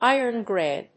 アクセントíron‐gráy[‐gréy]